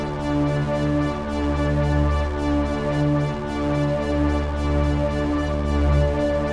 Index of /musicradar/dystopian-drone-samples/Tempo Loops/110bpm
DD_TempoDroneD_110-D.wav